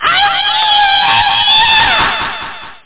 scream.mp3